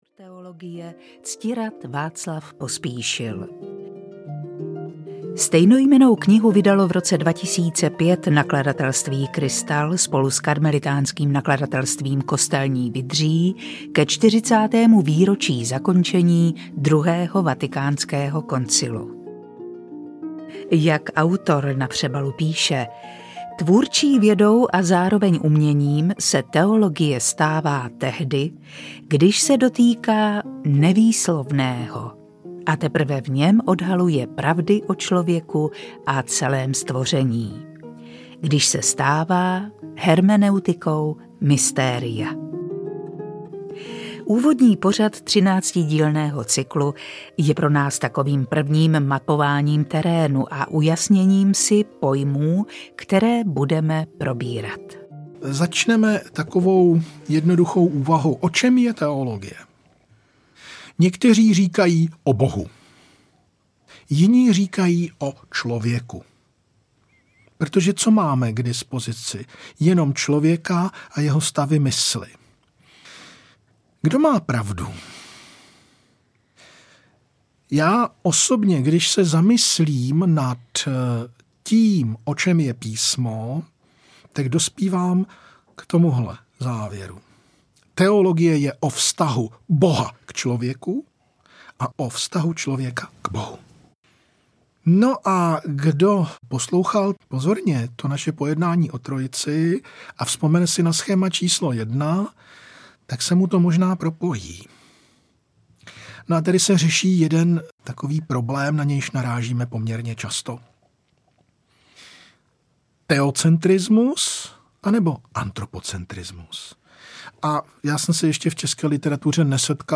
Hermeneutika mystéria audiokniha
Ukázka z knihy